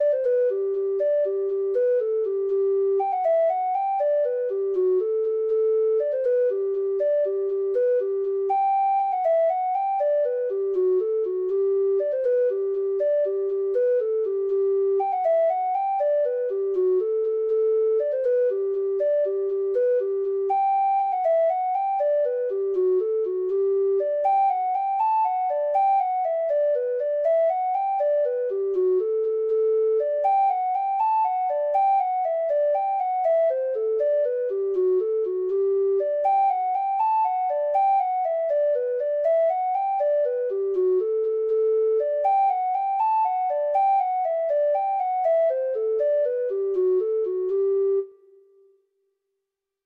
Traditional Trad. The New Pair of Shoes (Irish Folk Song) (Ireland) Treble Clef Instrument version
Traditional Music of unknown author.
Irish